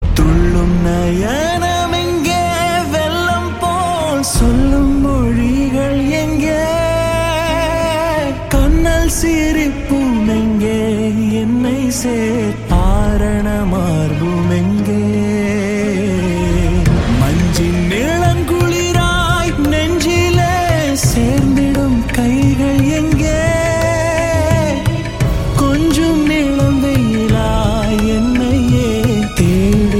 best flute ringtone download
romantic ringtone download